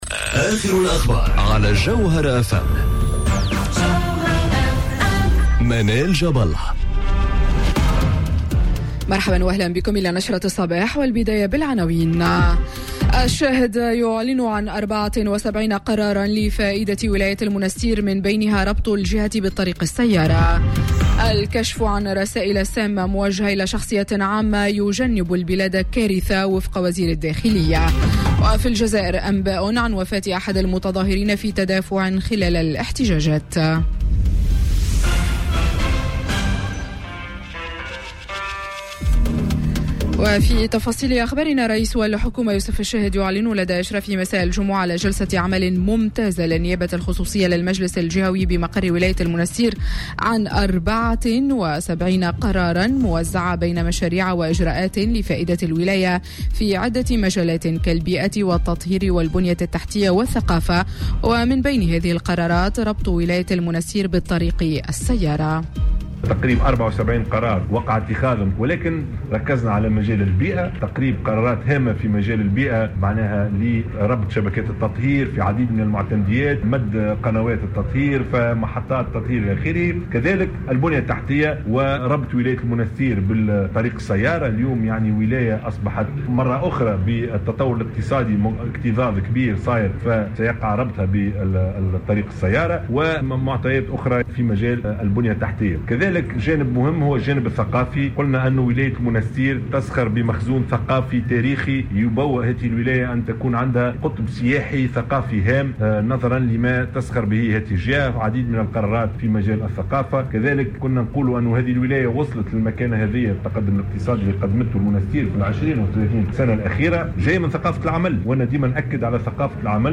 نشرة أخبار السابعة صباحا ليوم السبت 02 مارس 2019